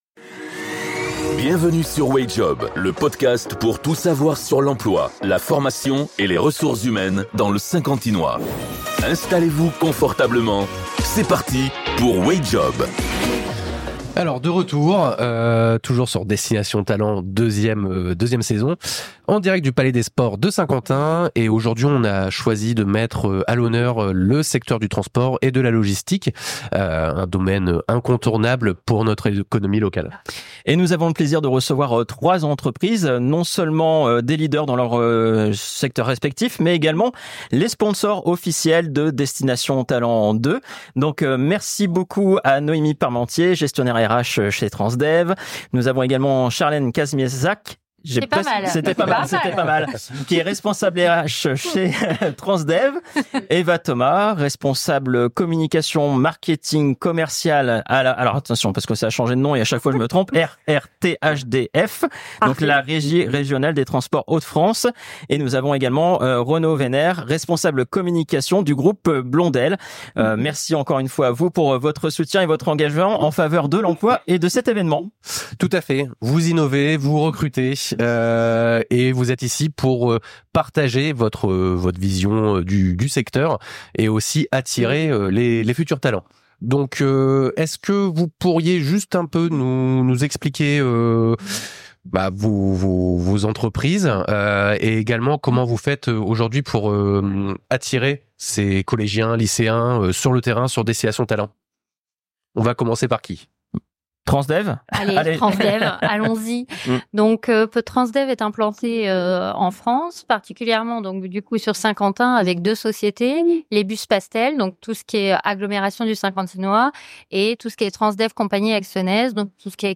Episode 15 - Table Ronde des Sponsors : Perspectives sur le Transport et la Logistique